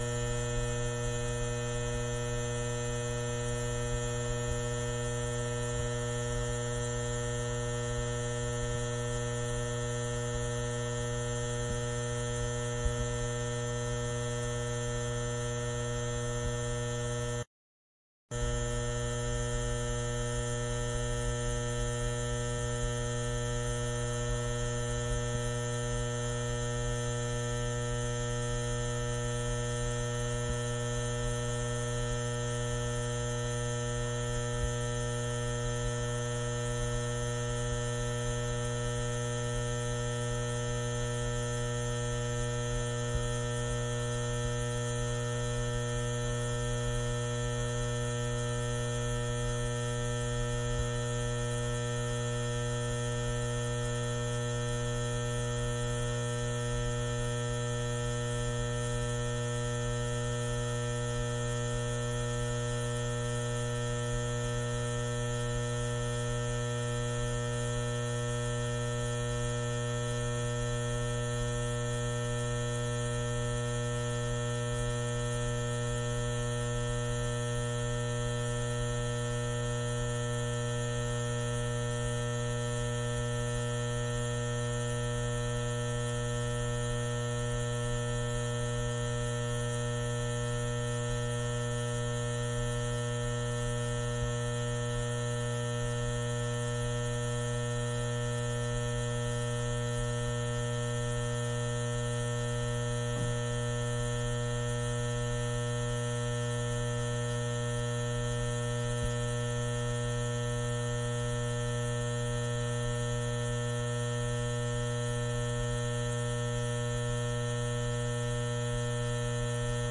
随机 " 霓虹灯的嗡嗡声 嗡嗡声立体声接近低切的味道 嗡嗡声
描述：霓虹灯嗡嗡声嗡嗡声立体声关闭低调品尝buzzy.flac
Tag: 关闭 嗡嗡声 低胸 符号 霓虹灯 嗡嗡声 立体声